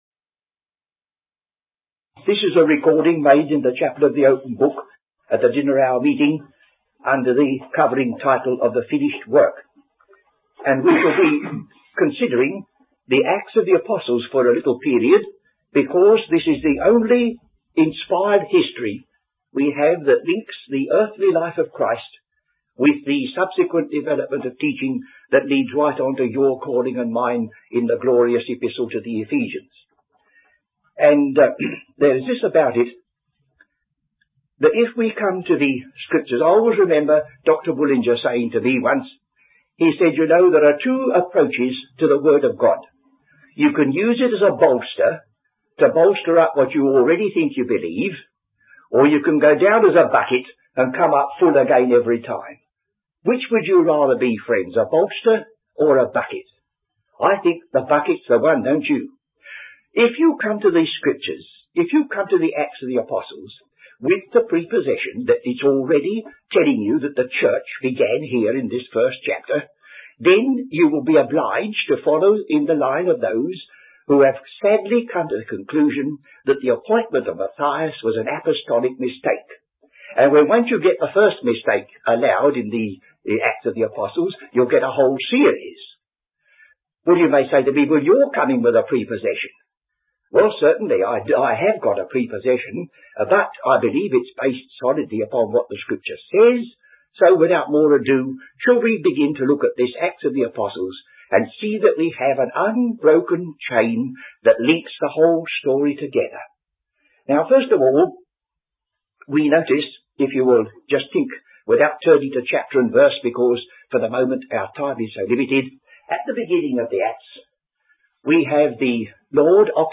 Teaching